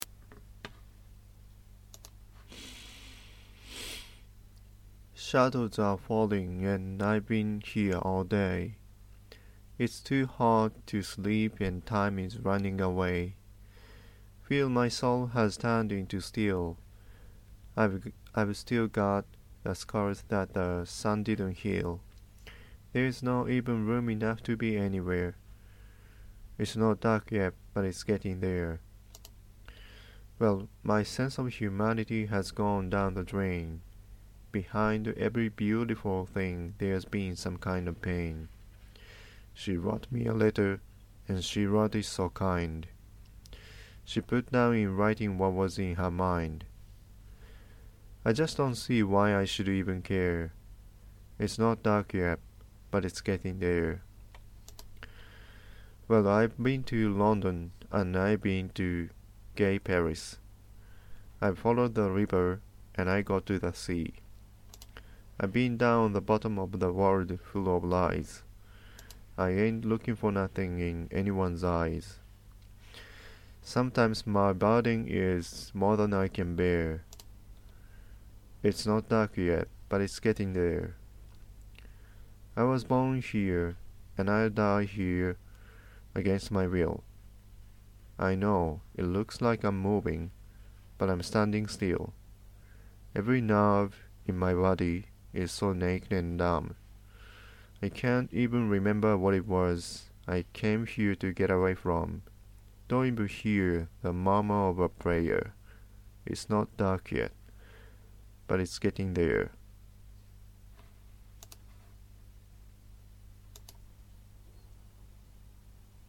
It's not dark yet(declamation)